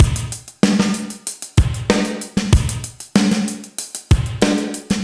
Index of /musicradar/80s-heat-samples/95bpm
AM_GateDrums_95-03.wav